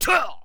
文件 文件历史 文件用途 全域文件用途 Enjo_atk_02_3.ogg （Ogg Vorbis声音文件，长度0.5秒，158 kbps，文件大小：9 KB） 源地址:地下城与勇士游戏语音 文件历史 点击某个日期/时间查看对应时刻的文件。